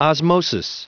Prononciation du mot osmosis en anglais (fichier audio)
Prononciation du mot : osmosis